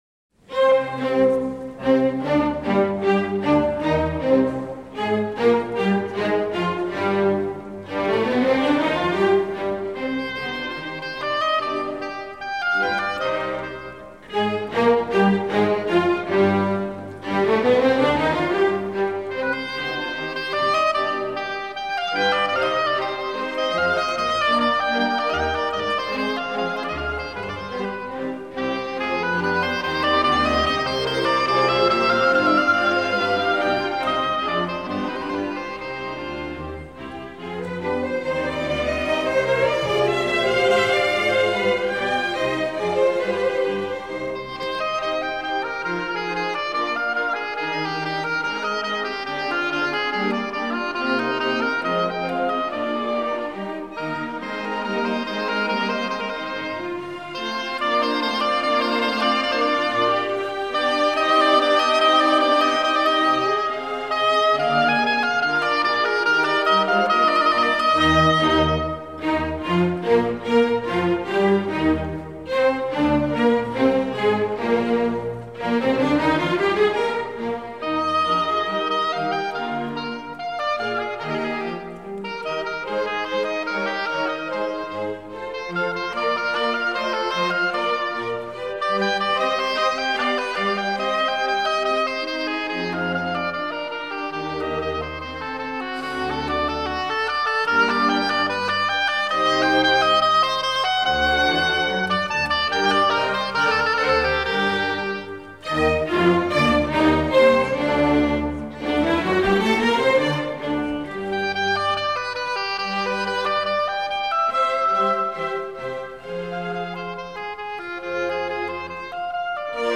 Heinz Holliger, oboe. Alessandro Marcello: Allegro moderato-Adagio-Allegro. From "Oboe Concerto in C minor".
Orchestre de la Suisse Romande.